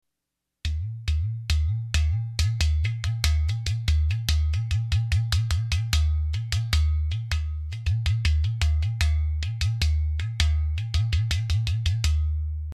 Wood Fired Udu Drum
A wood fired clay jug drum. This drum is played by slapping and cupping your hand over the hole in the side of the drum and along the sides of the jug.
ududrum.mp3